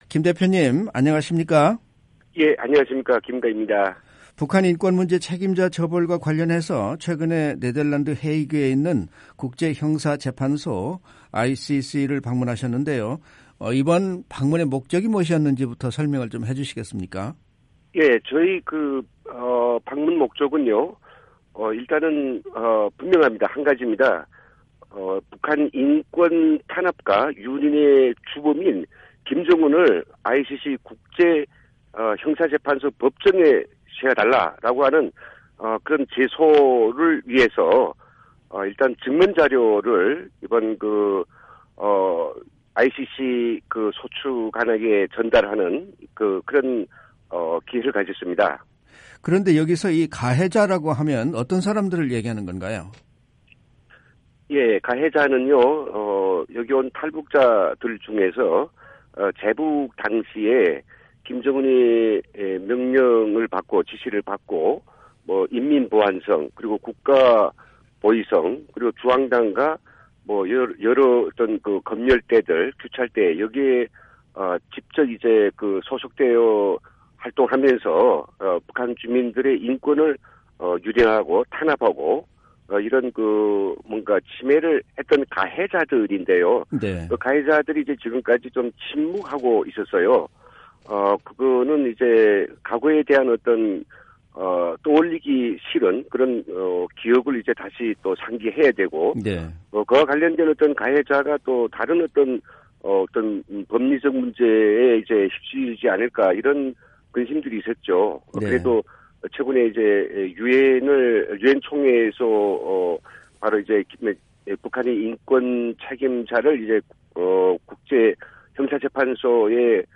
인터뷰 오디오